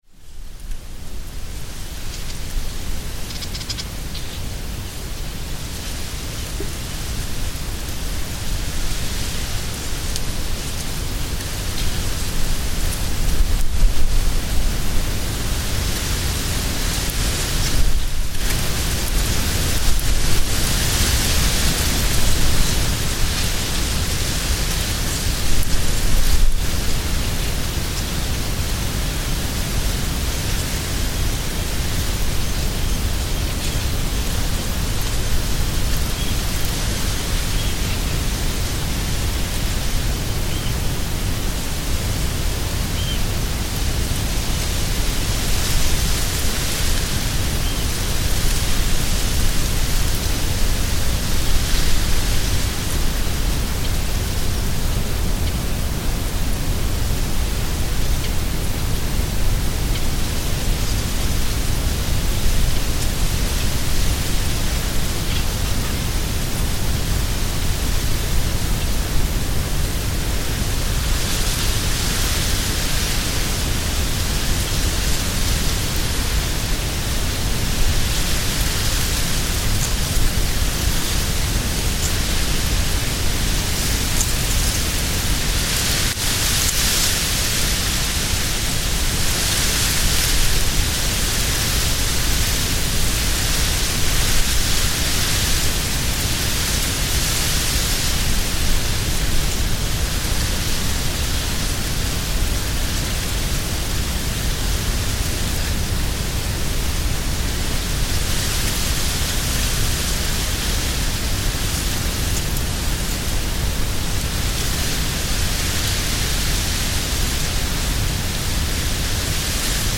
Wind in the reeds
Wind blows gently in the reeds on a wild driftwood beach only accessible by boat in the Po Delta, Italy, with periodic bird calls also audible, June 2025.